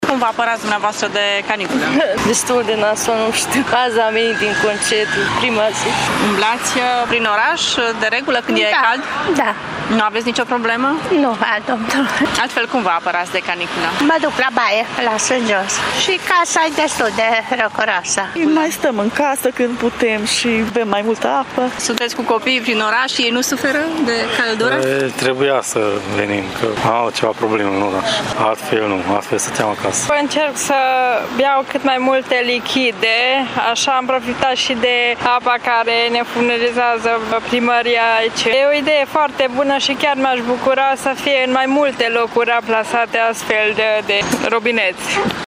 Târgumureșenii afirmă nu suferă de caniculă și că știu cum să se ferească de temperaturile prea ridicate: